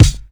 Kicks
Dj_Premier kick.wav